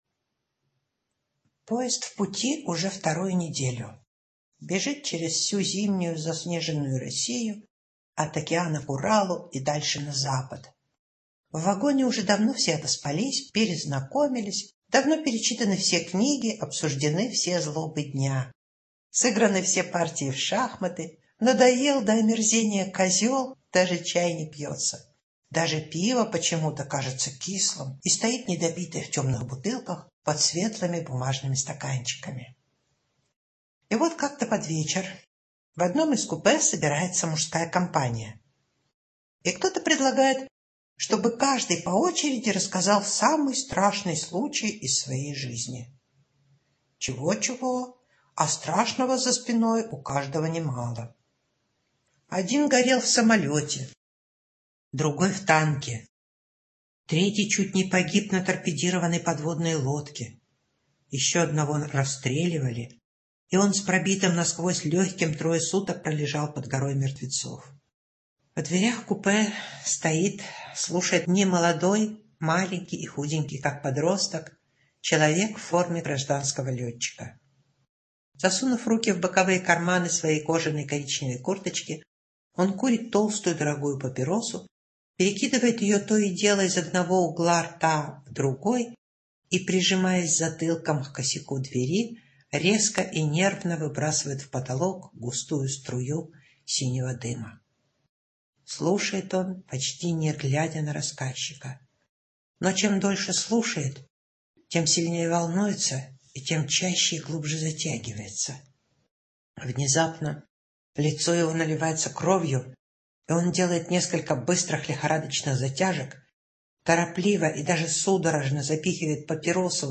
Аудиорассказ «Кожаные перчатки»